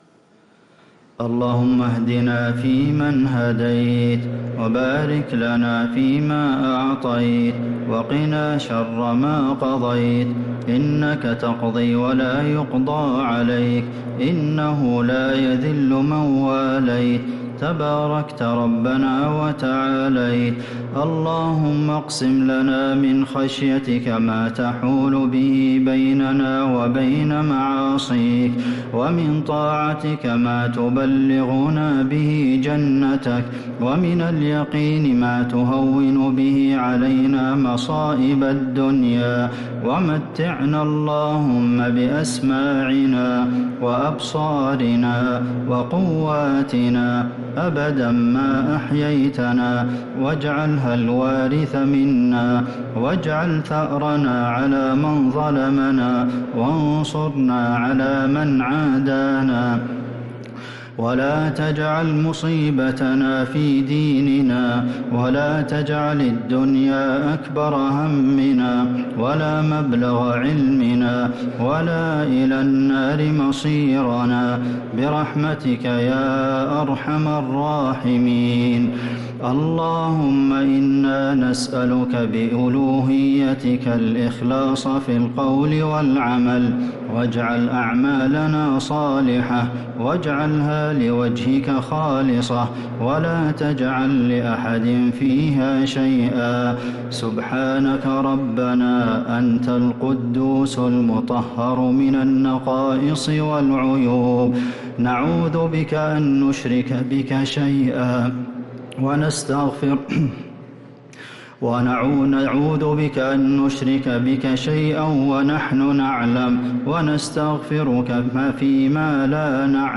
دعاء القنوت ليلة 4 رمضان 1446هـ | Dua 4th night Ramadan 1446H > تراويح الحرم النبوي عام 1446 🕌 > التراويح - تلاوات الحرمين